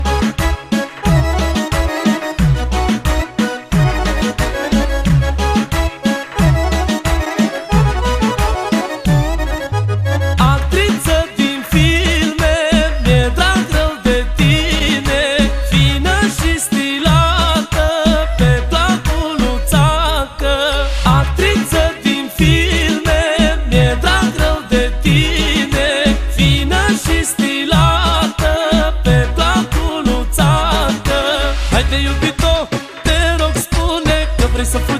Жанр: Танцевальная музыка